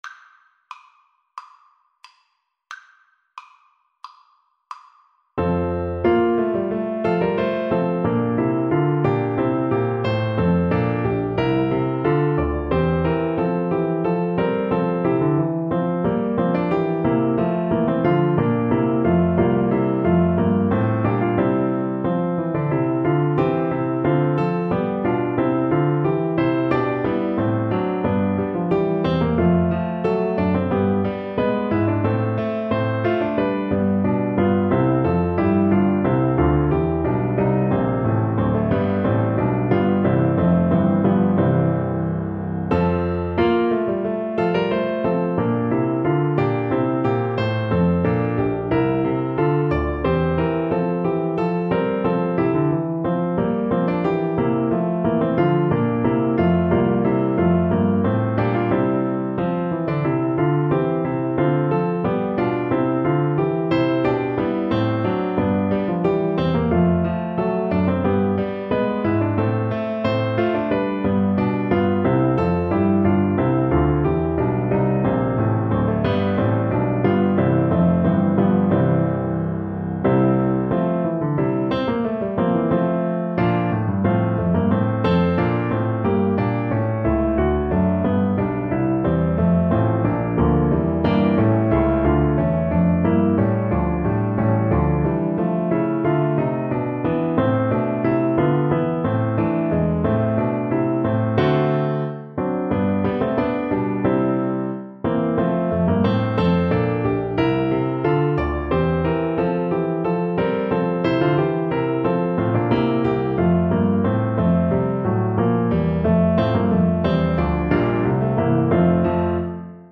4/4 (View more 4/4 Music)
= 90 Allegro (View more music marked Allegro)
Classical (View more Classical Recorder Music)